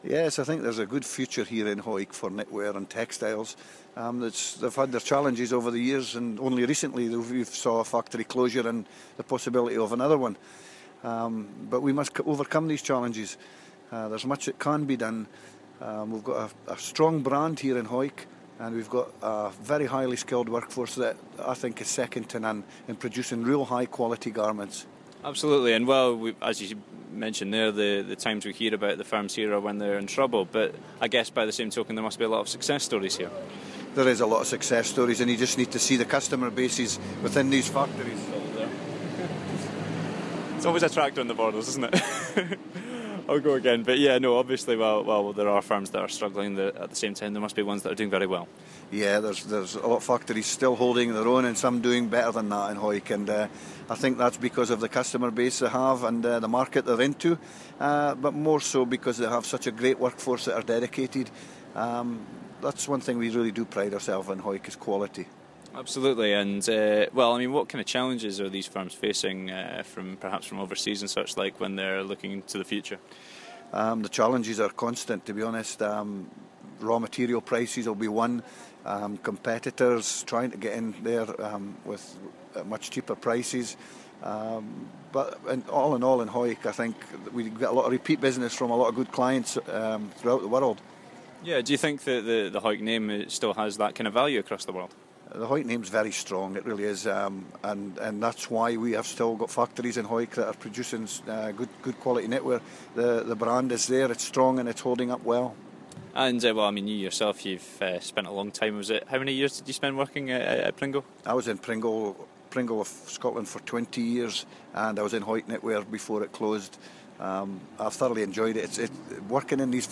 Following a raft of job losses earlier this year, and the possibility of more to come, we spoke to Hawick Provost and former textiles worker Stuart Marshall on the future of the industry.